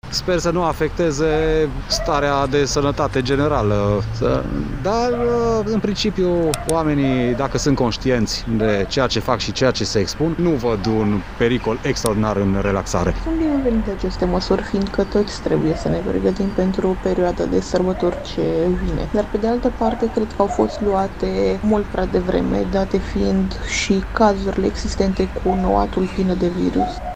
Mureșenii par mulțumiți de noile relaxări și nu au temeri mari legate de noua variantă a coronavirusului, Omicron: